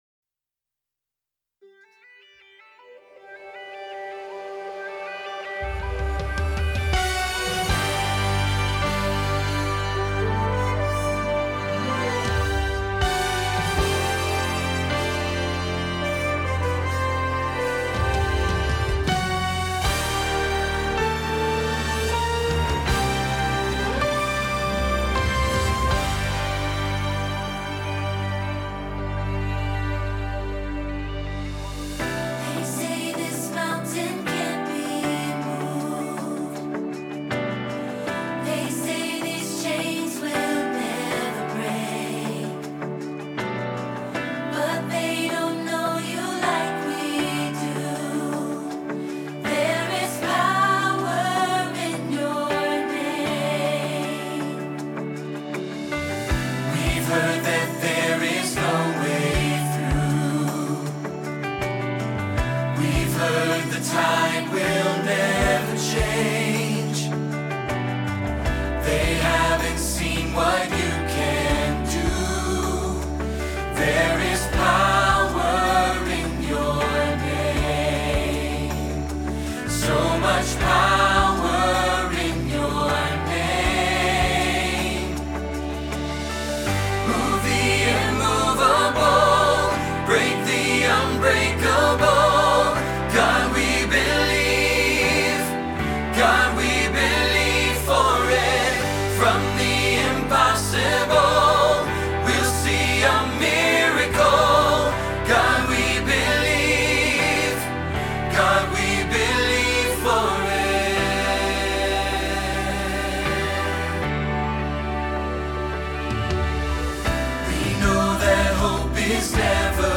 Believe for It – Tenor – Hilltop Choir
Believe-for-It-Tenor-edit.mp3